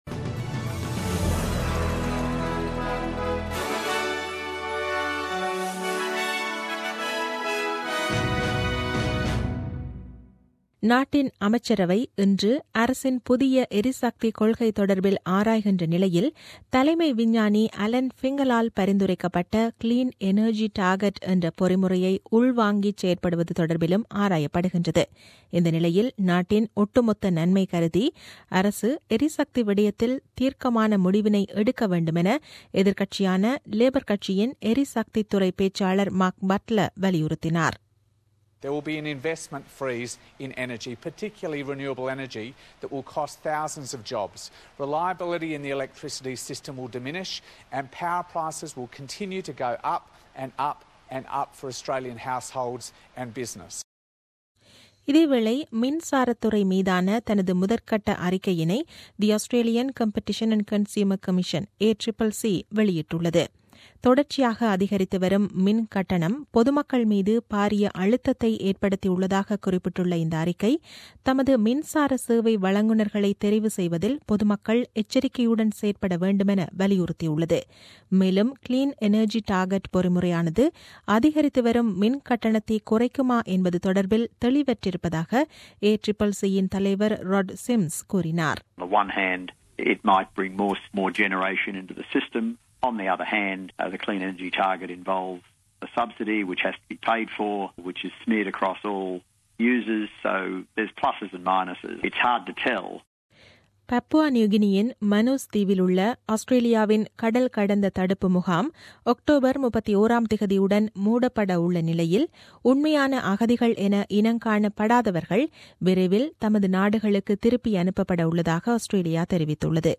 The news bulletin aired on 16 October 2017 at 8pm.